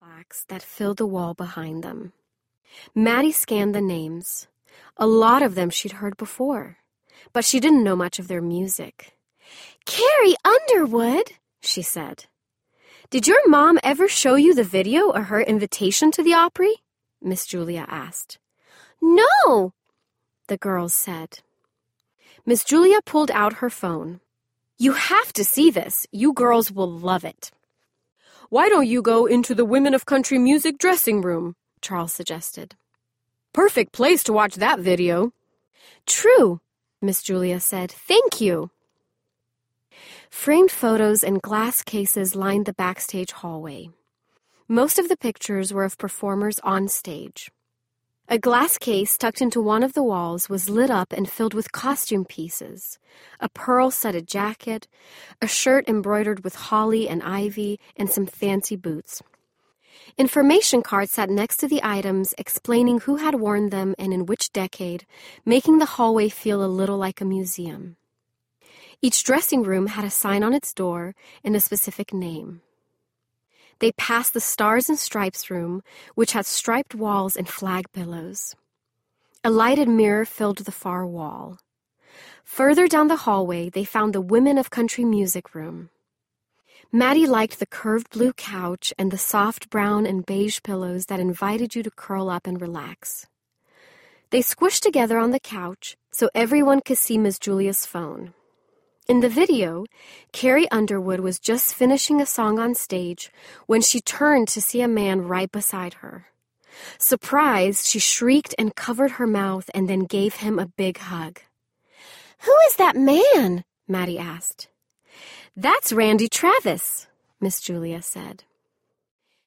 Miracle in Music City (Faithgirlz/Glimmer Girls Series, Book #3) Audiobook
4.2 Hrs. – Unabridged